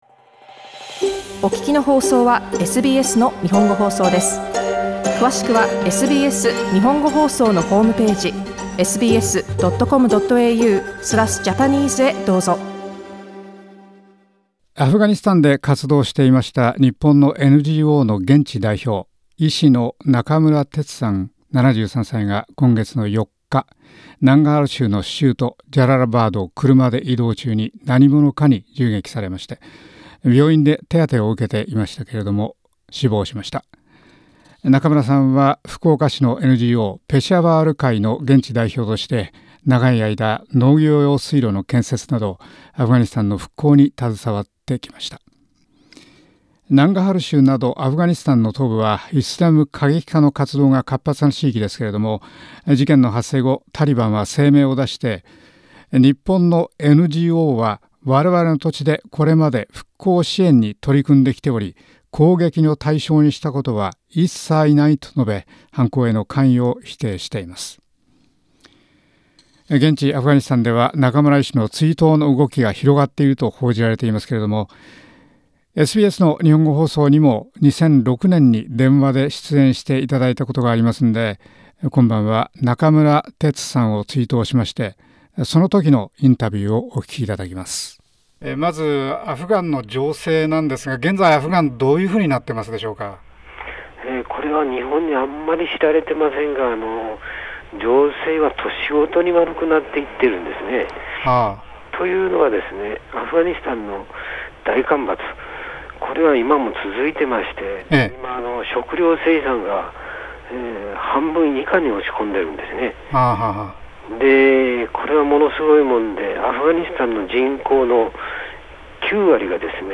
アフガニスタンで復興に携わっていた日本のNGO、ペシャワール会の現地代表、中村哲医師がジャララバードを車で移動中に銃撃され死亡しました。アフガニスタンの復興に全力を傾けた中村医師の死を悼んで、2006年にSBSラジオ日本語放送に電話で出演していただいたときの録音をお聞きいただきます。